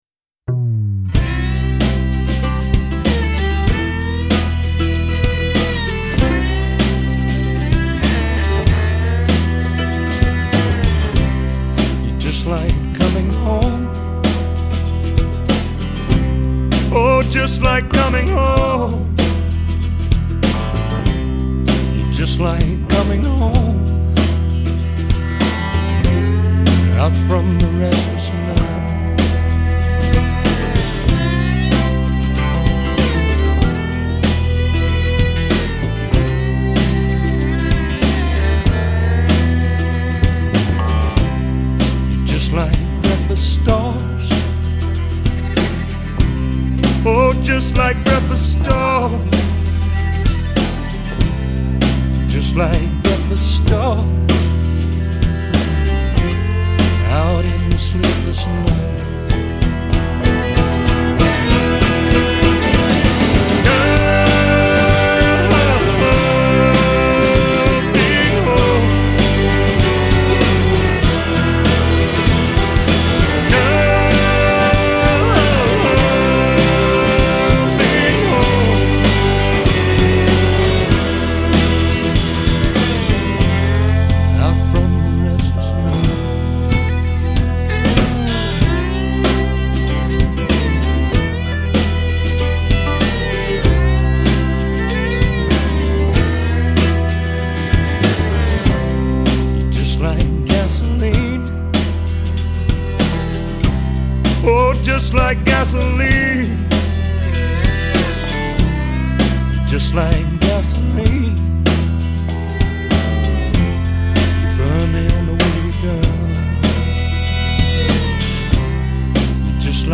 Punk / Bluegrass band